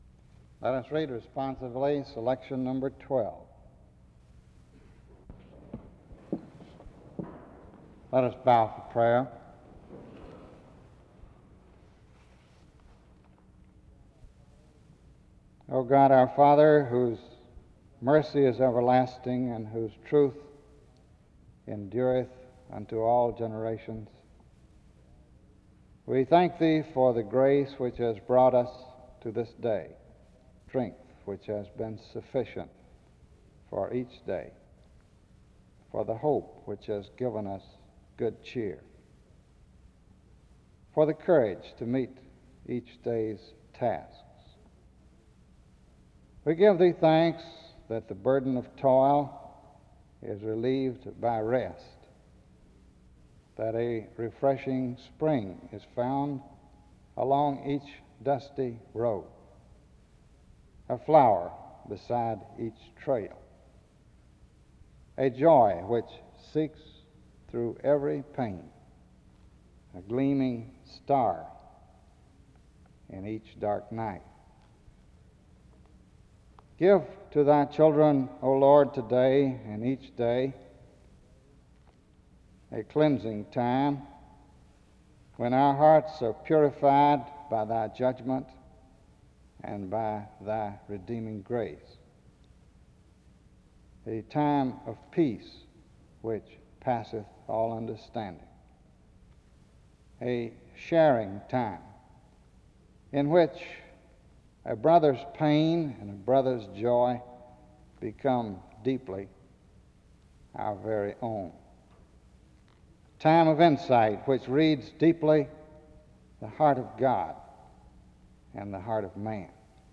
The service begins with a word of prayer from 0:00-2:48.